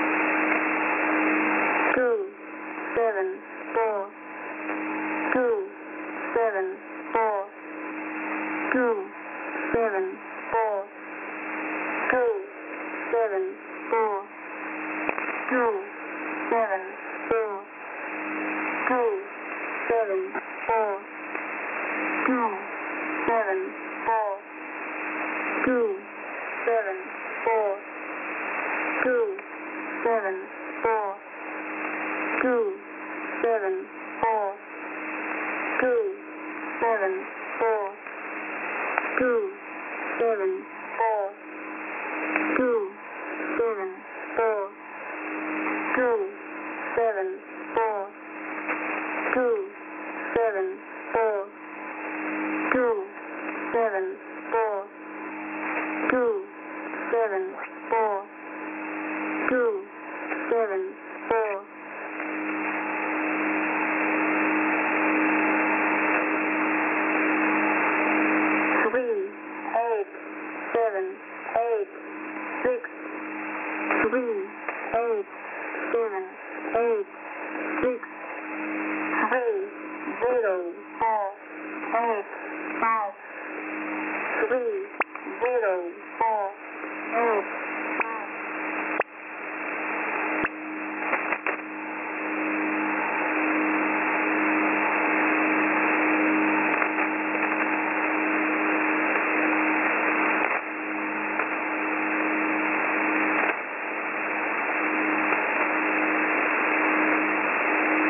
描述：ai人声英文数字4的发音，时长900ms
声道单声道